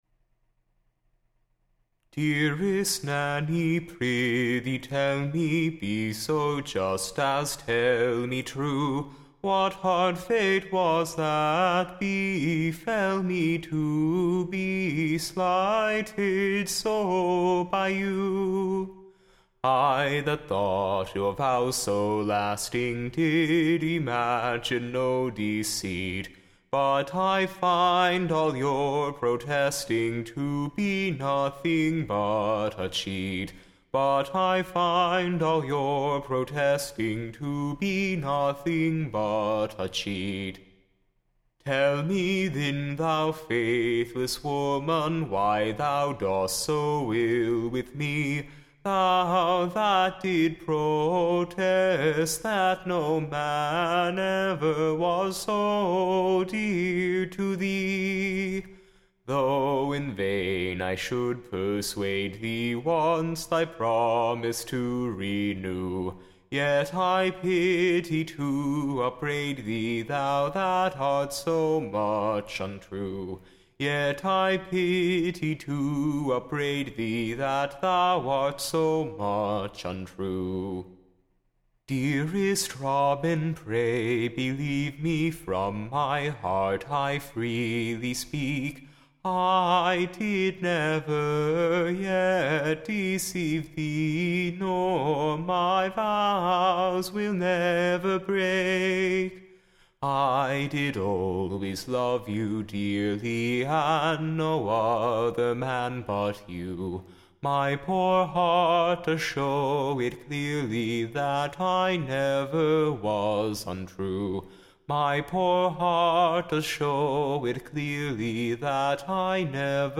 Recording Information Ballad Title Doubtful ROBIN; / Or, Constant NANNY. / A New BALLAD.